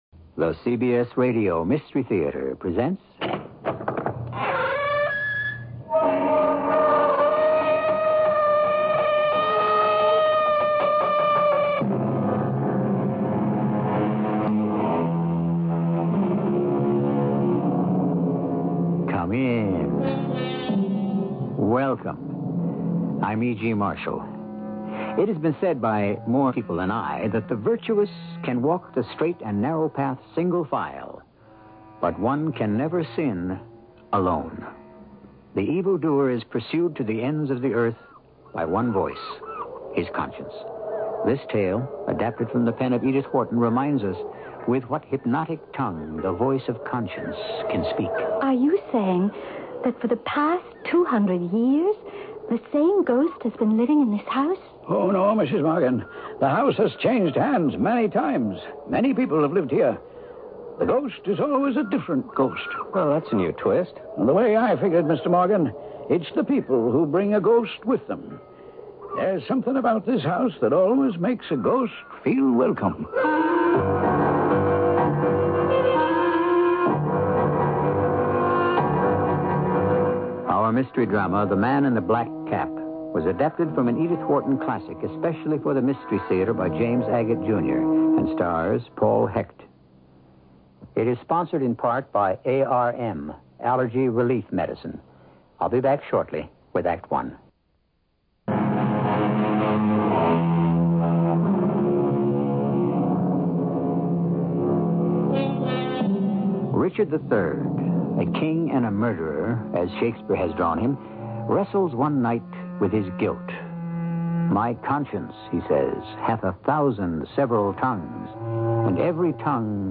On this episode of the Old Time Radiocast we present you with two stories from the classic radio program CBS Radio Mystery Theater!